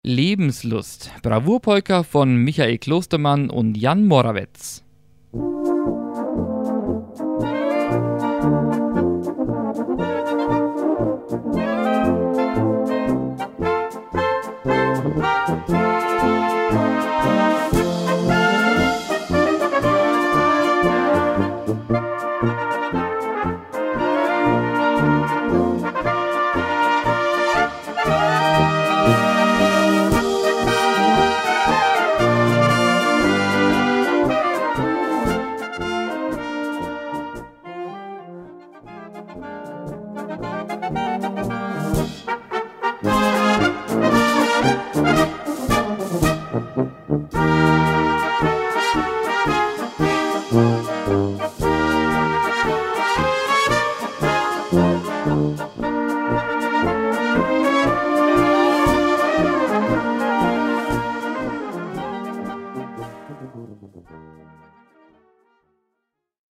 Gattung: Bravour-Polka
A4 Besetzung: Blasorchester Zu hören auf